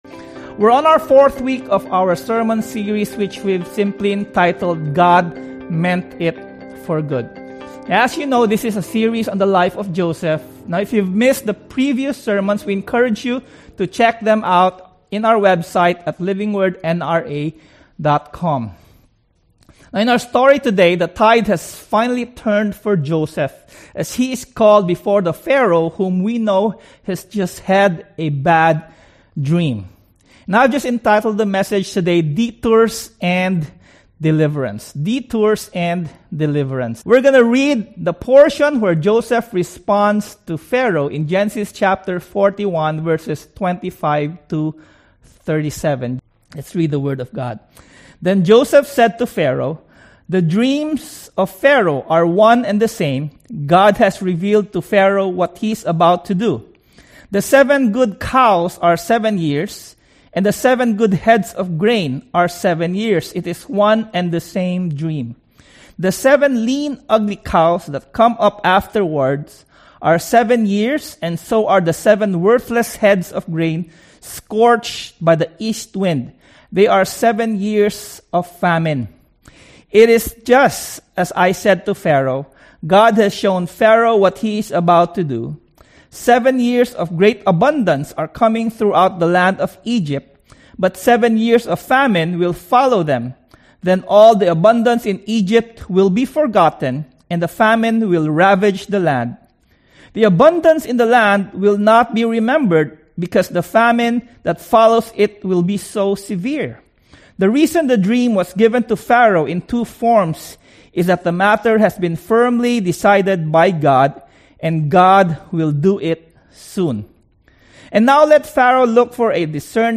Sermon #4: Detours & Deliverance